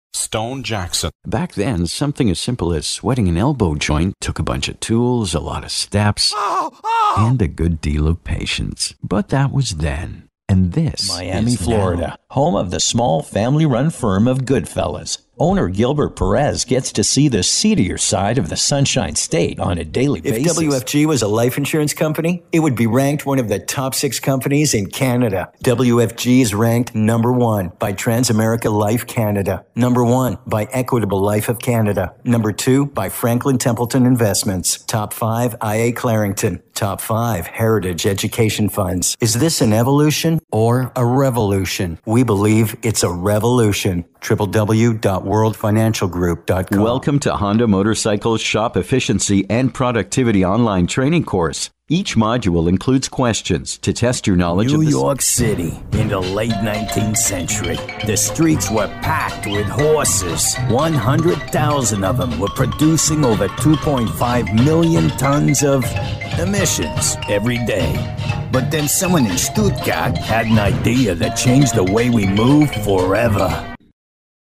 I'm a full-time Canadian male voice talent with my own professional home studio in Vancouver Canada.
Sprechprobe: Industrie (Muttersprache):
My vocal arsenal runs the gamut from the guy next door to wry to selected character and ethnic voices. I deliver a natural and believable read that engages the intended target audience and gets your product/service and brand the attention it deserves!